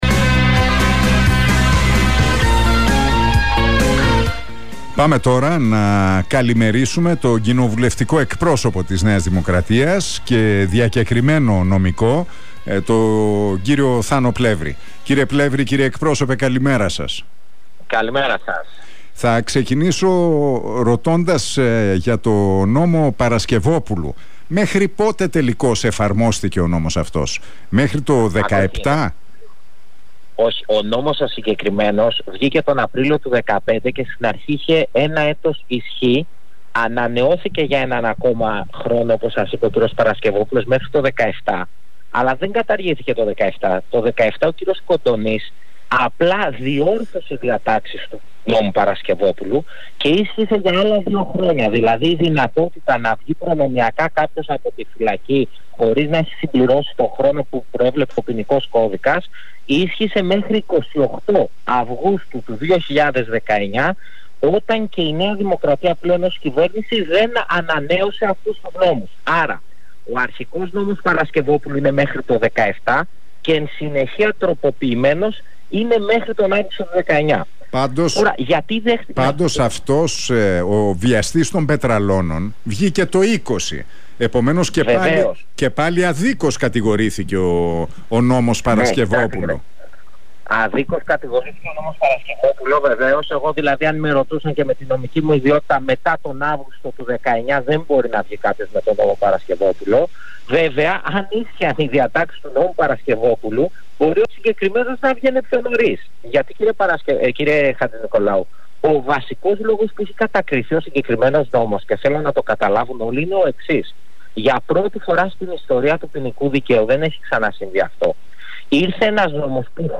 Ο αναπληρωτής κοινοβουλευτικός εκπρόσωπος της ΝΔ, Θανάσης Πλεύρης, μίλησε στον Realfm 97,8 και στην εκπομπή του Νίκου Χατζηνικολάου.